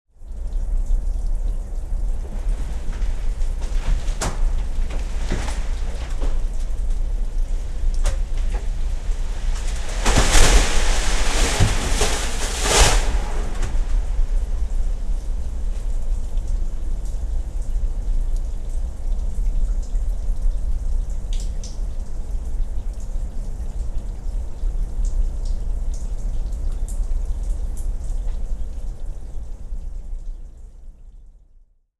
testimonianza sonora della “sofferenza” dei ghiacciai.